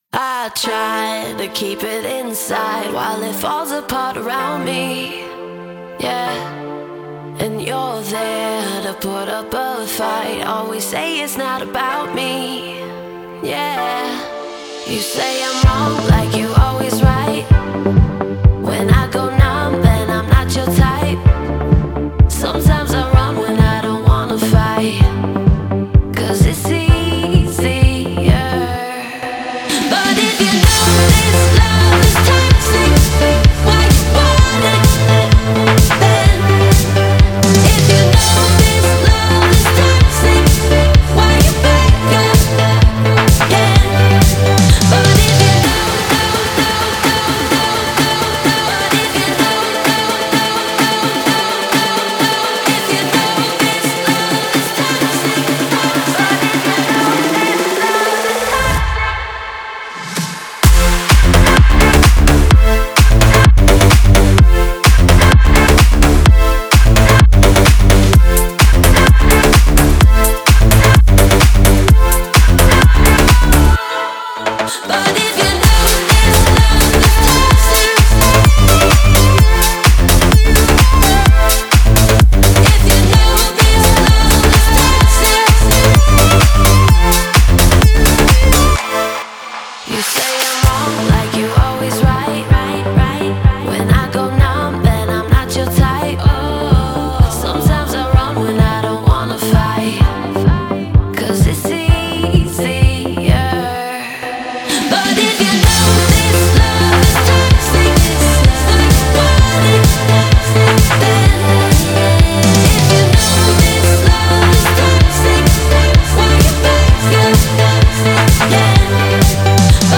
это захватывающий трек в жанре EDM